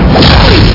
Amiga 8-bit Sampled Voice
punch.mp3